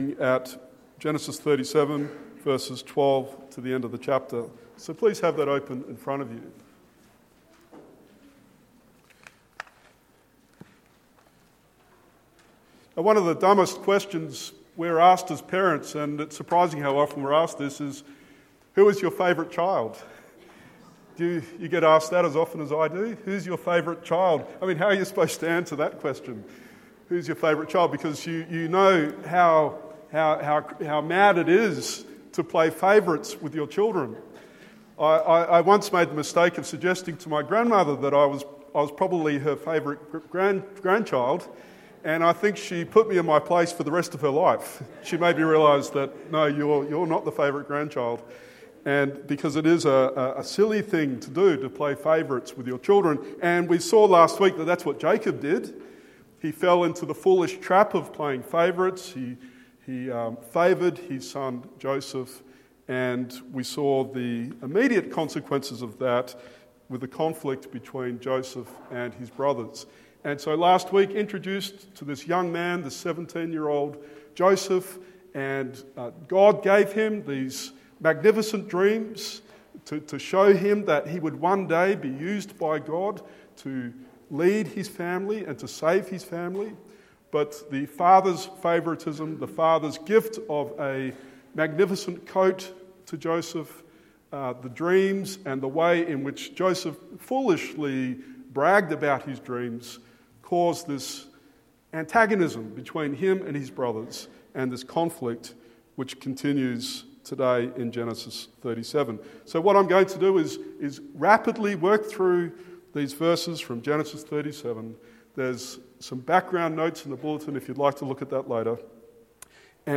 Genesis 37:1-11 Sermon